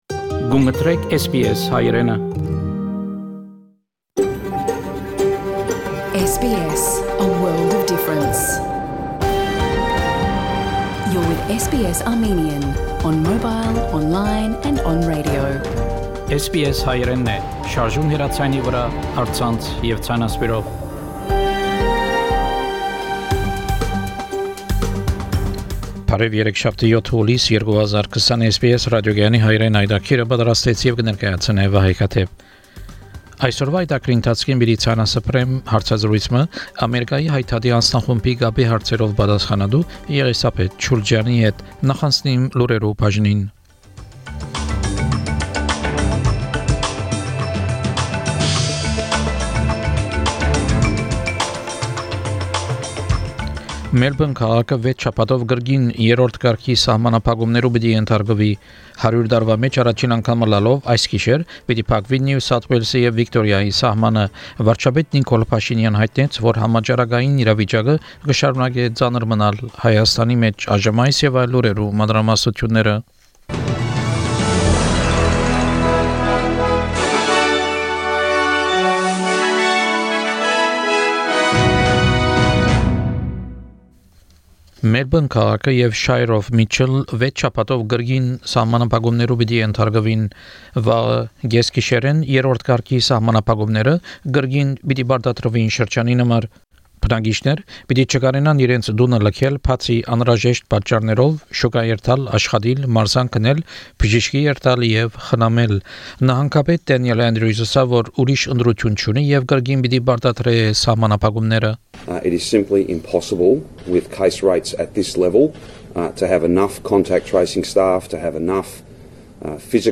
SBS Armenian news bulletin – 7 July 2020